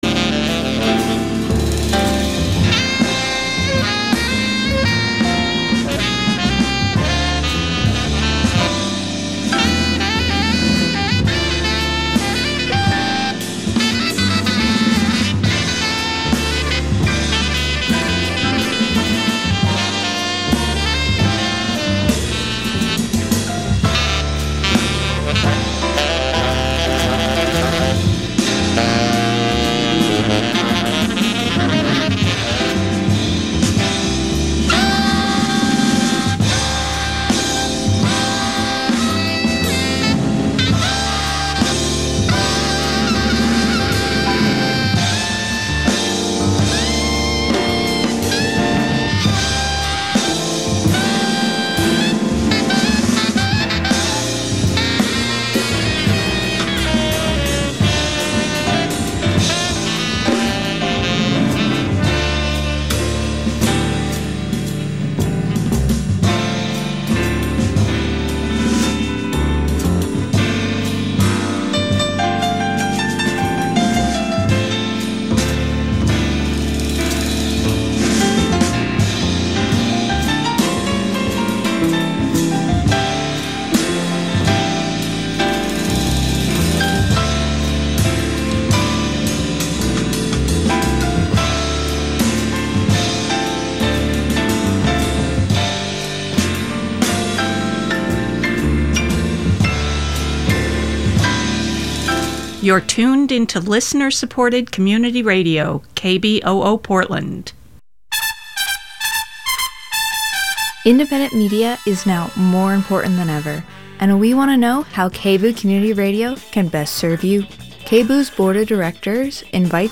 The Commons Foreign Affairs Committee of the UK Parliament met February 3 to take oral evidence from experts on the western hemisphere and international law on the question: What can we learn from Venezuela?